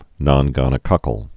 (nŏngŏn-ə-kŏkəl)